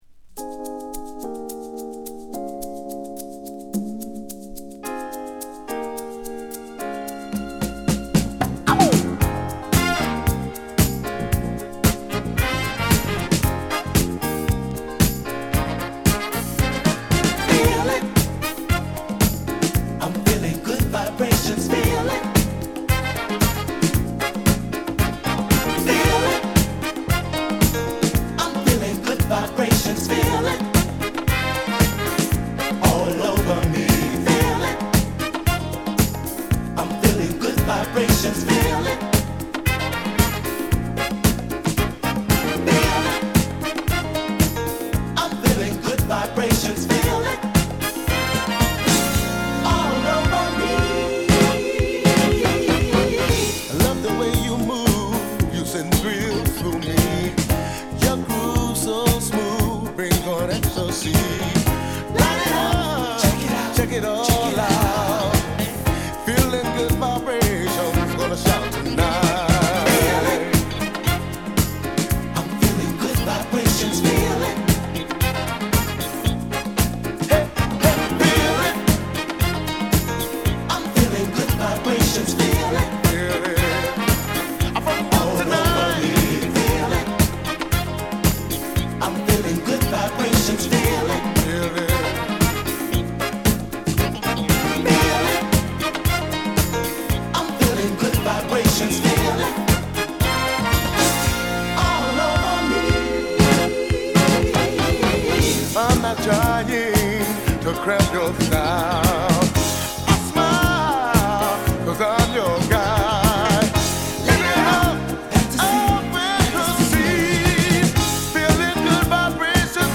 メロウからダンスまで堪能できる充実のモダン・ソウル・アルバム！！...